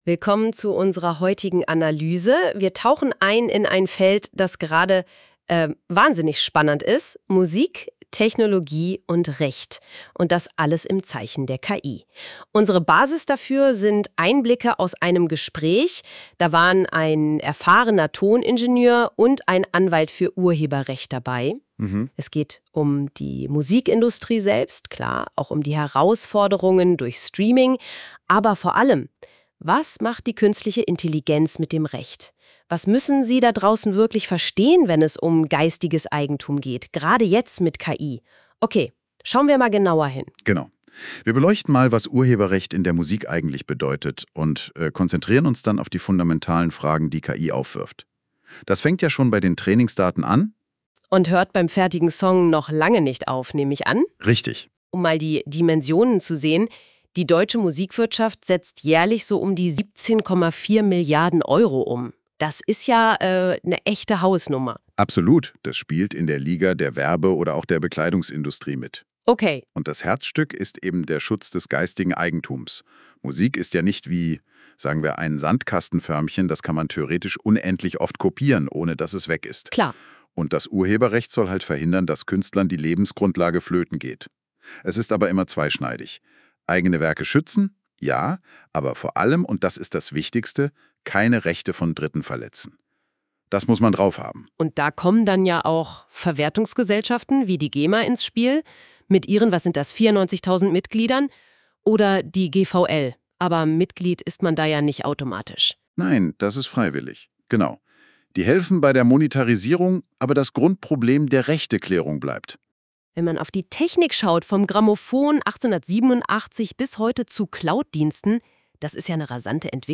KI und Musik - Podcast zum Urheberercht